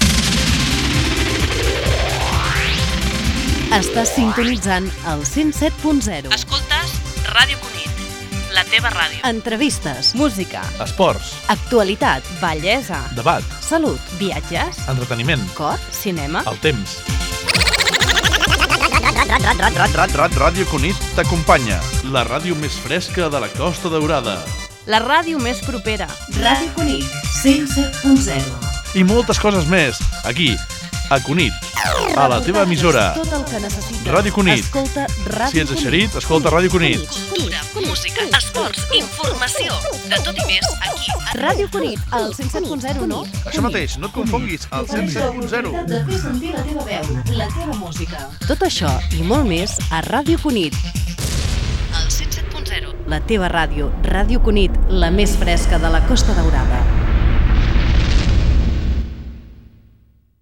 Indicatiu de l'emissora
Dia de l'emissió inaugural.